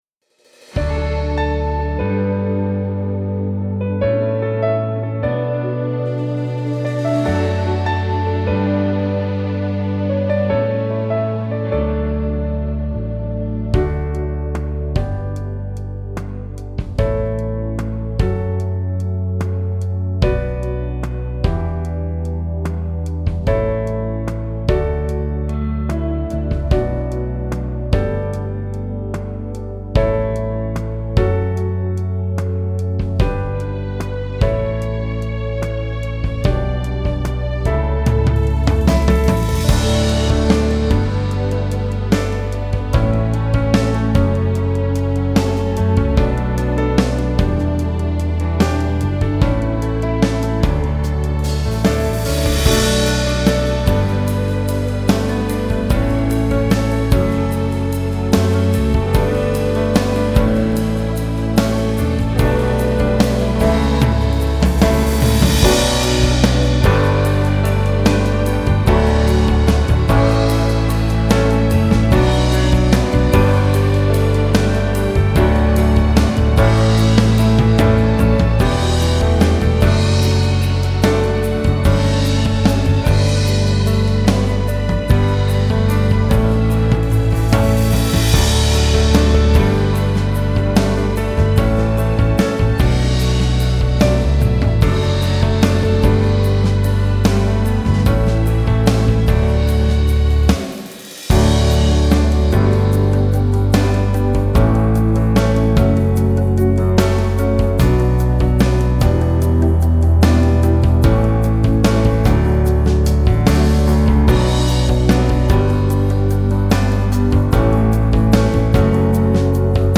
keyboard
drums